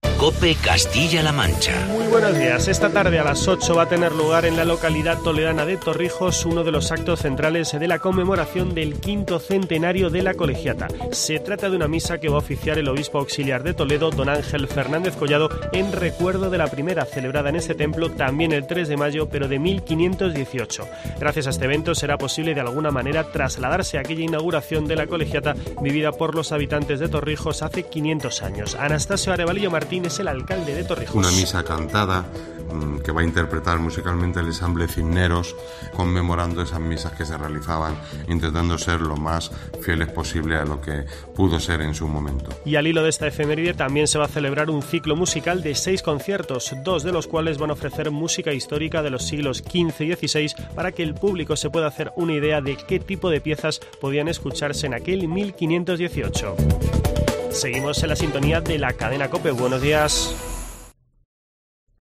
Boletín informativo de COPE Castilla-La Mancha.